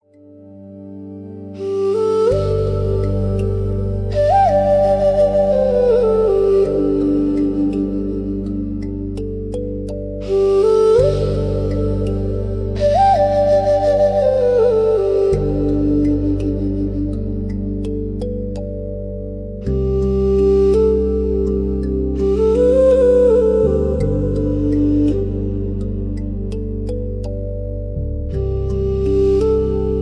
Background instrumental Music